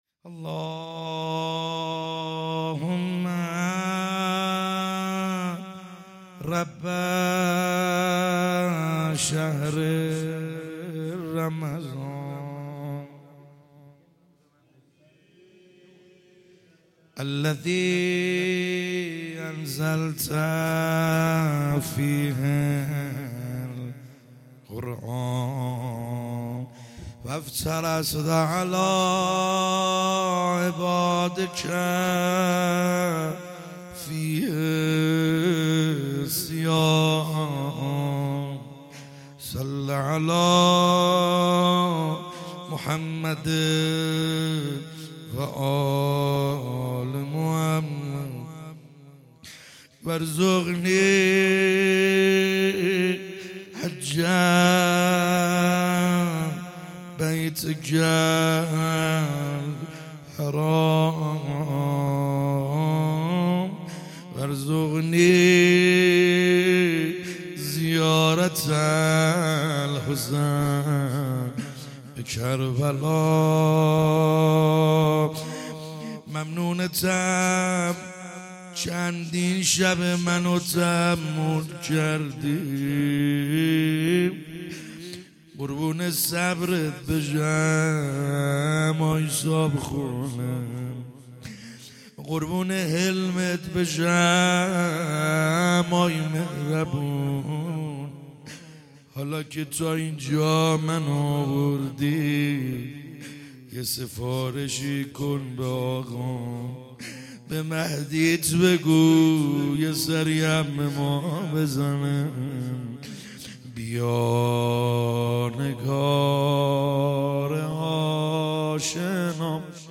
خیمه گاه - بیرق معظم محبین حضرت صاحب الزمان(عج) - مناجات ا شب سیزدهم